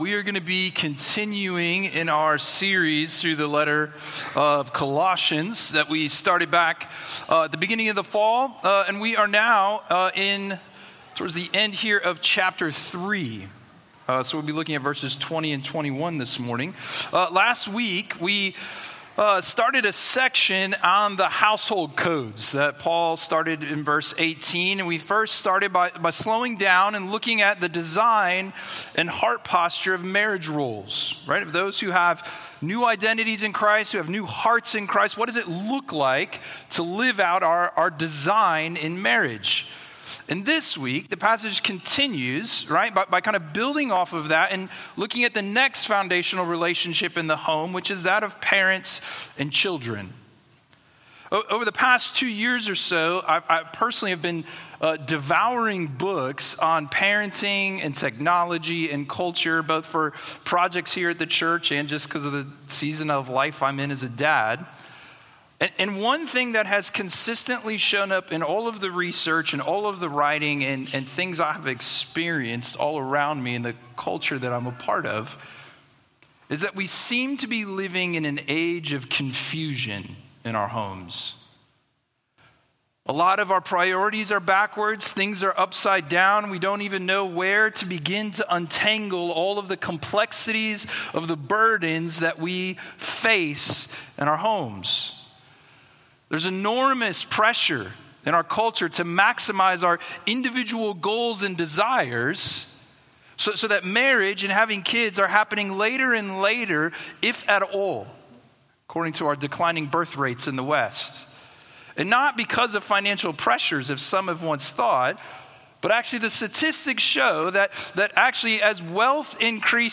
Sermon11-16-25.mp3